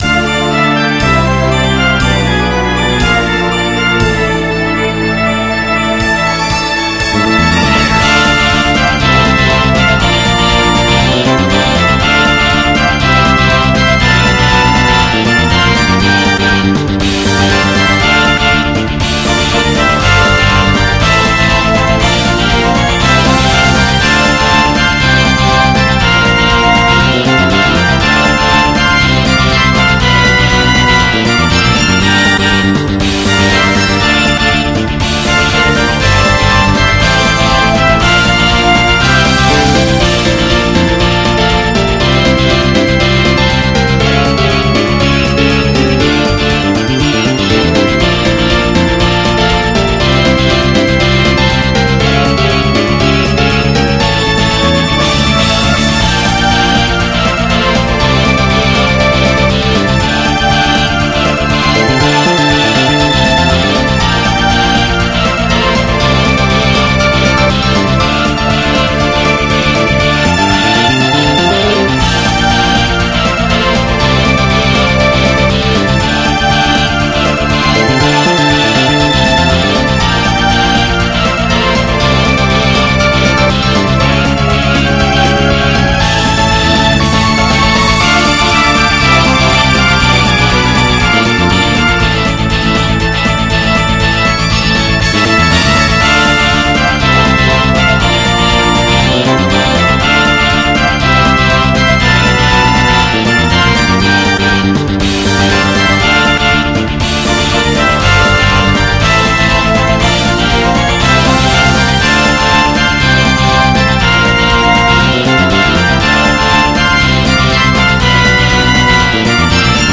MIDI 84.7 KB MP3 (Converted) 2.89 MB MIDI-XML Sheet Music